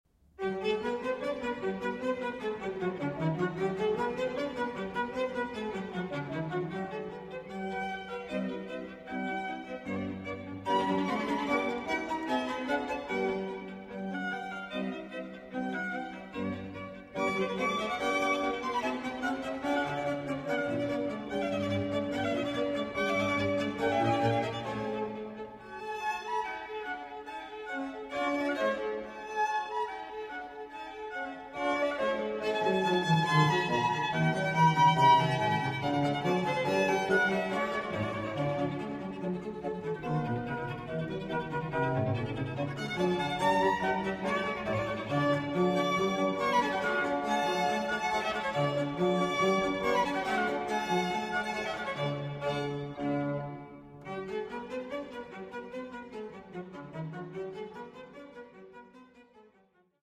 G major